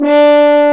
Index of /90_sSampleCDs/Roland LCDP12 Solo Brass/BRS_French Horn/BRS_Mute-Stopped
BRS F.HORN0M.wav